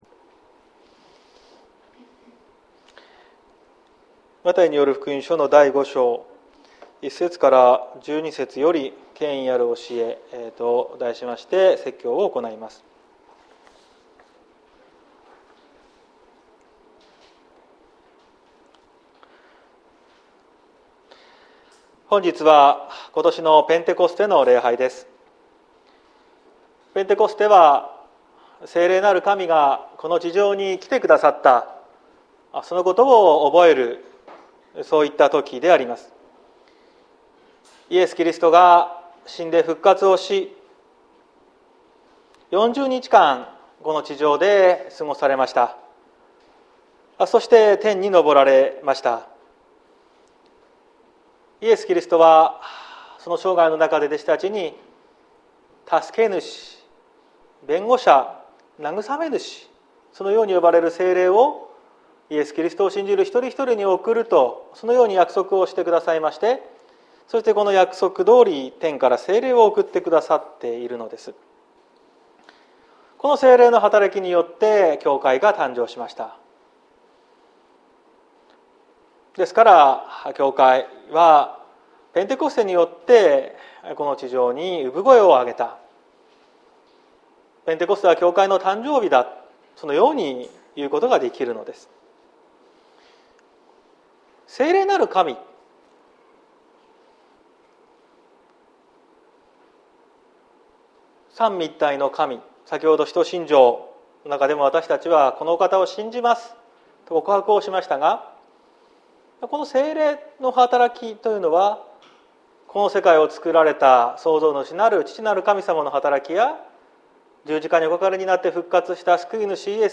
2022年06月05日朝の礼拝「権威ある教え」綱島教会
説教アーカイブ。